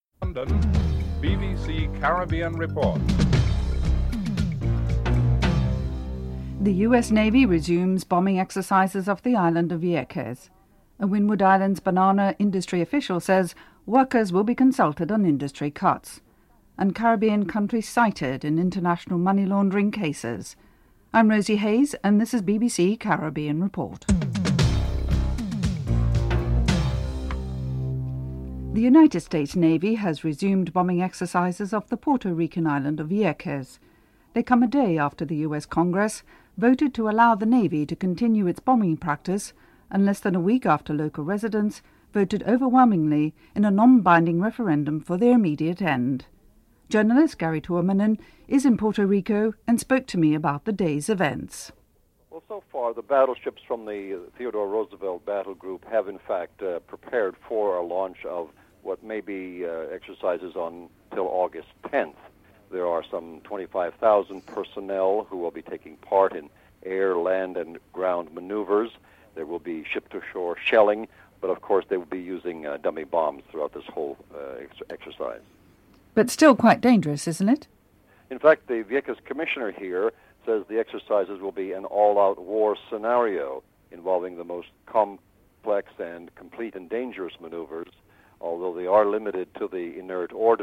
1. Headlines (00:00-00:27)
6. Opposition Party in Trinidad and Tobago has declined to speculate on the possibility of forming a government after a court decision on Tuesday went against a constitutional motion sort by the ruling party. Senator Glenda Morean is interviewed (09:33-12:01)